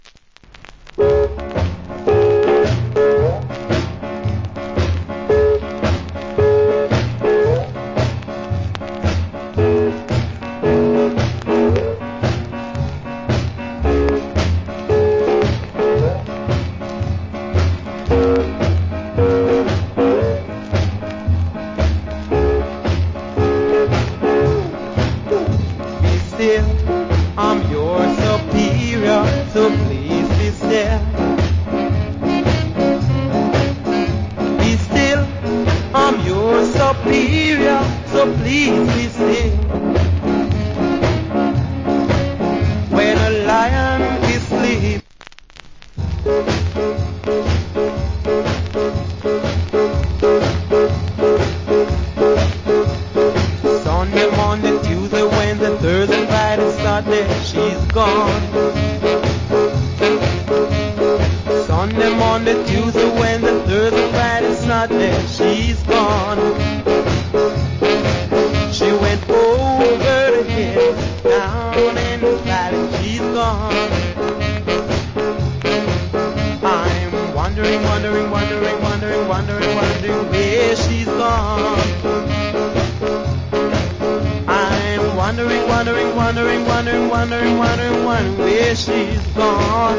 Early 60's Shuffle.